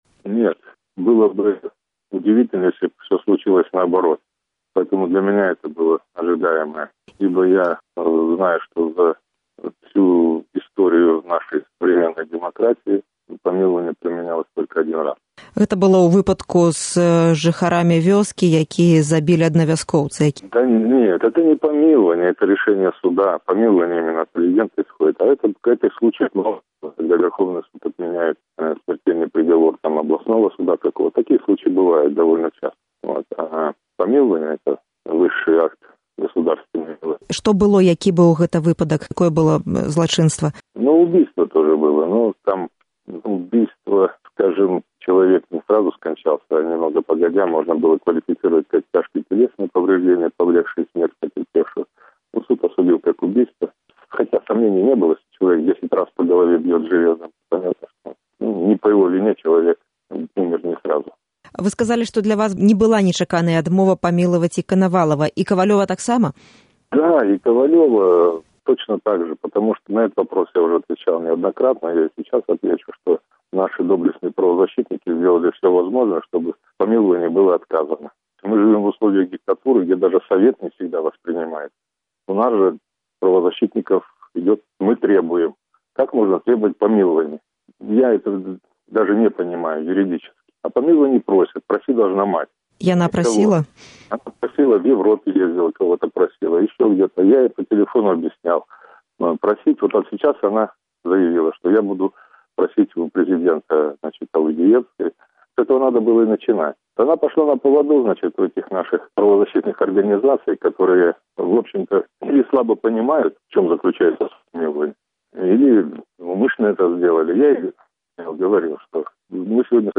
Інтэрвію з Алегам Алкаевым